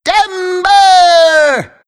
Worms speechbanks
Fatality.wav